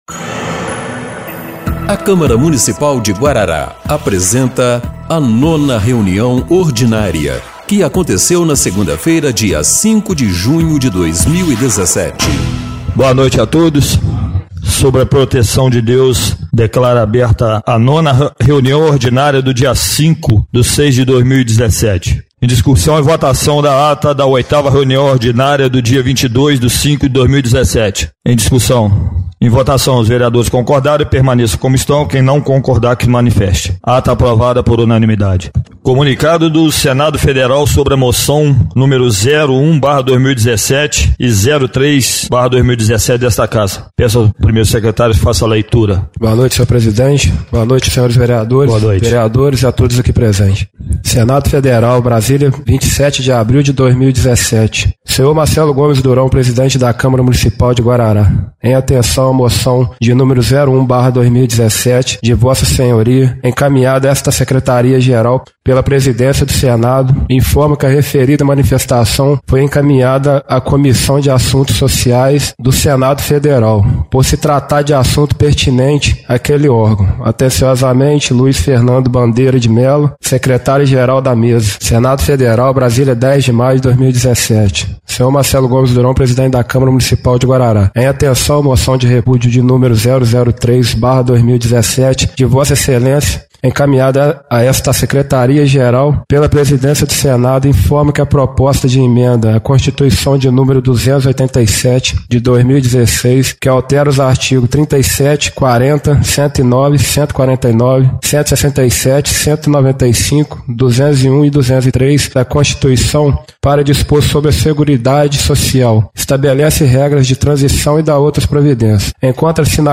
9ª Reunião Ordinária de 05/06/2017